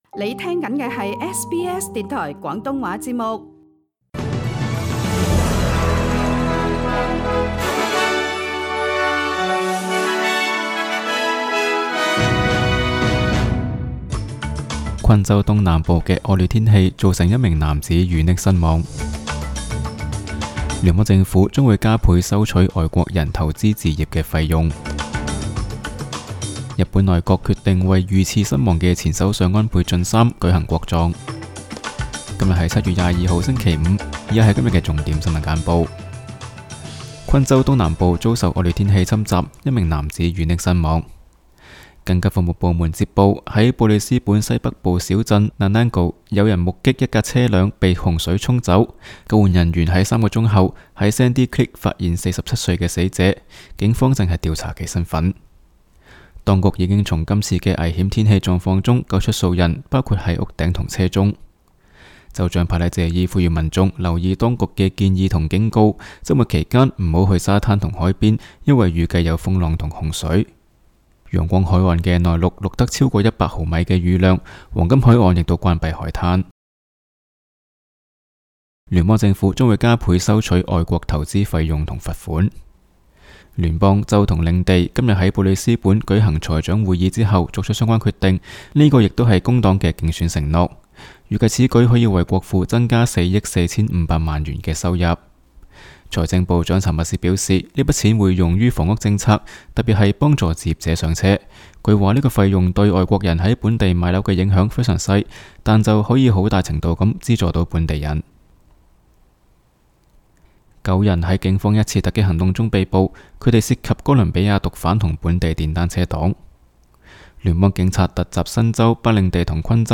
SBS 新闻简报（7月22日）